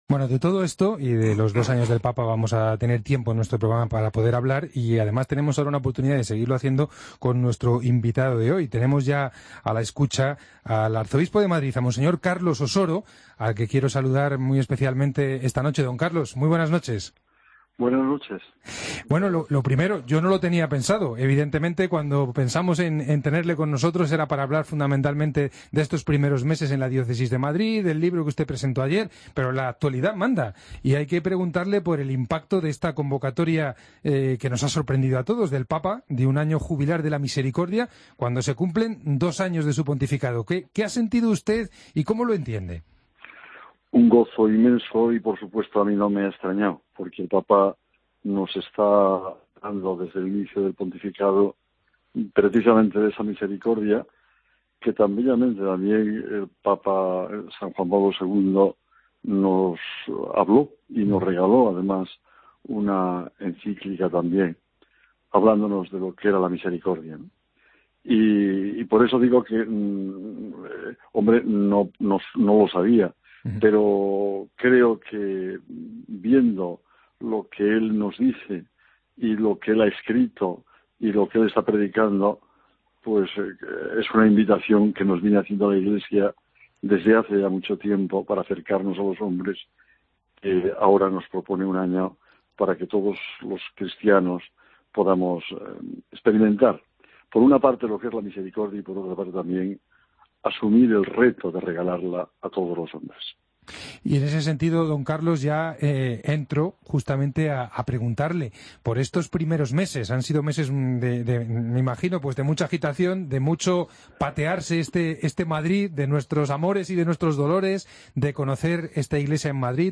Escucha la entrevista a Monseñor Osoro en La Linterna de la Iglesia
AUDIO: El Arzobispo de Madrid habló este viernes en los micrófonos de la Cadena COPE de los dos años de Pontificado de Francisco.